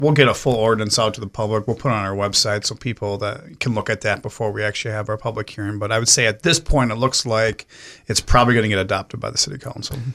Jaunich says the public will get a chance to see the draft ordinance before the public hearing: